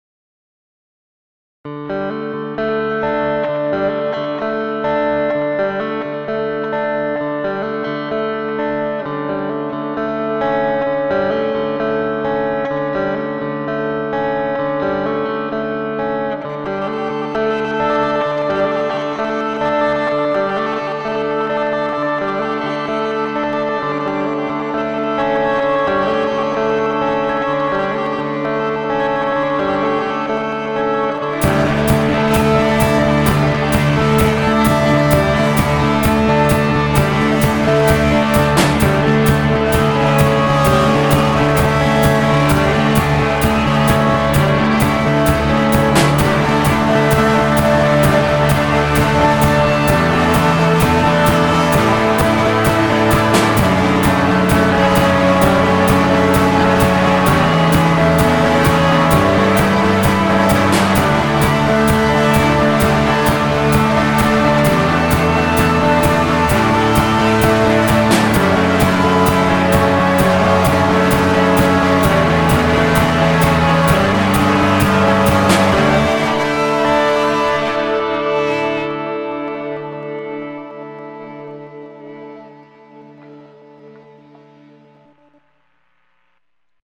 NES tune in Furnace, mixed in Ardour.